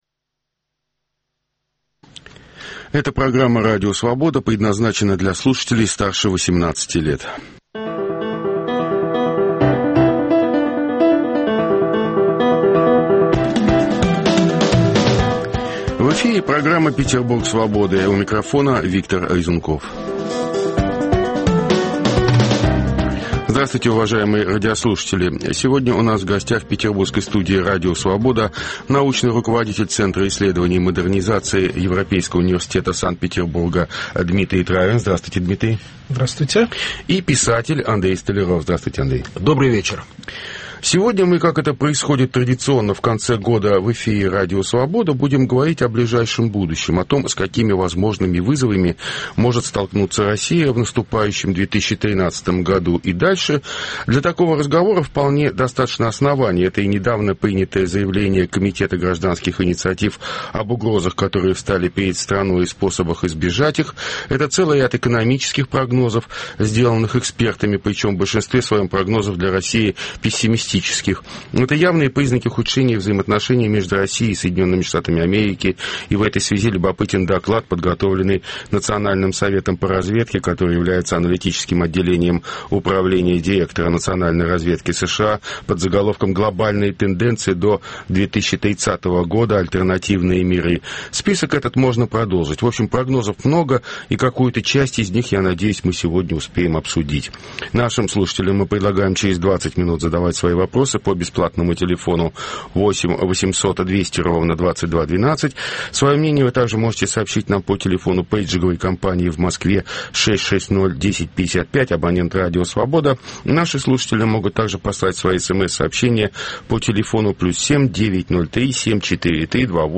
Круглый стол: Петербург Свободы